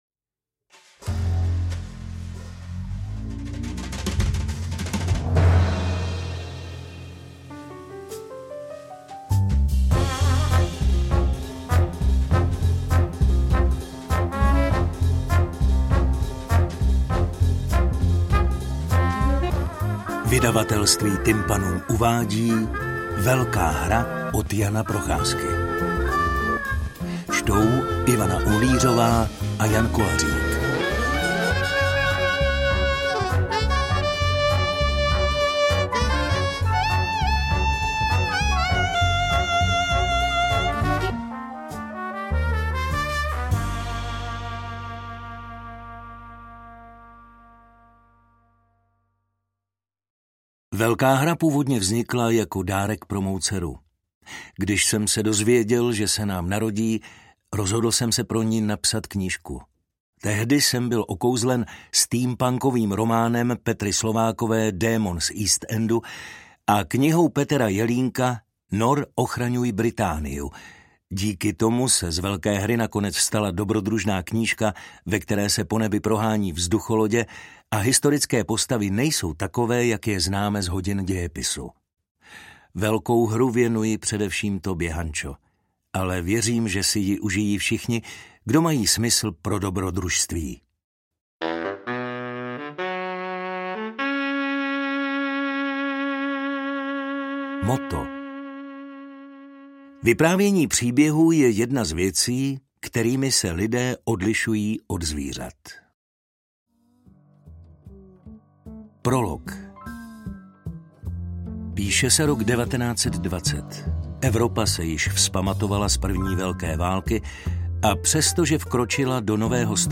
AudioKniha ke stažení, 7 x mp3, délka 2 hod. 59 min., velikost 163,8 MB, česky